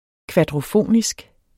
Udtale [ kvadʁoˈfoˀnisg ]